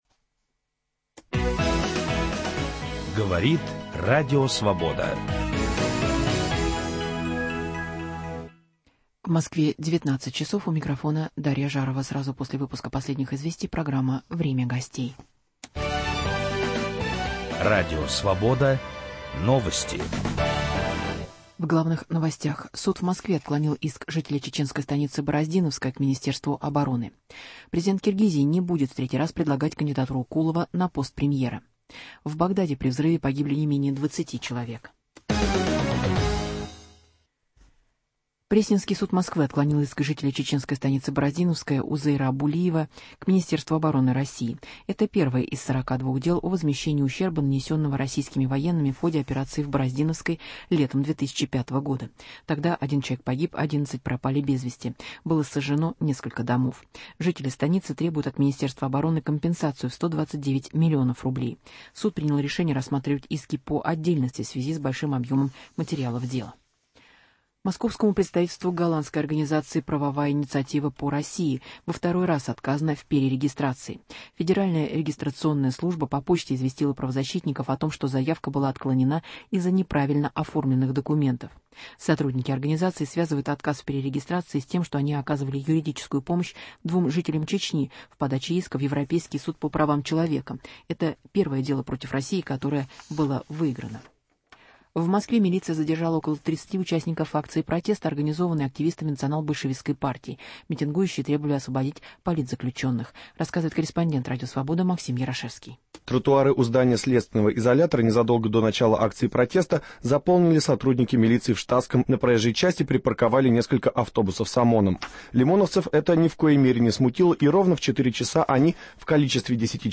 Радиомост Москва-Страсбург. В программу приглашен руководитель российской делегации в ПАСЕ, глава думского комитета по международным делам Константин Косачев.